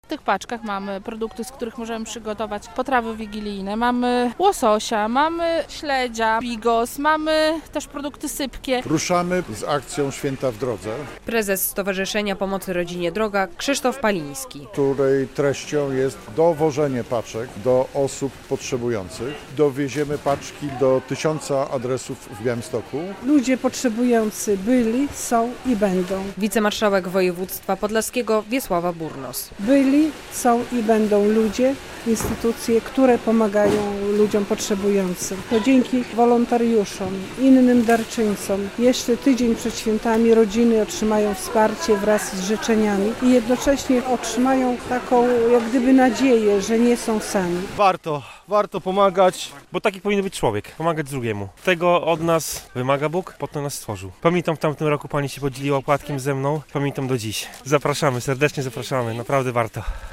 Jak dodaje wicemarszałek województwa podlaskiego Wiesława Burnos, ludzie potrzebujący byli, są i będą.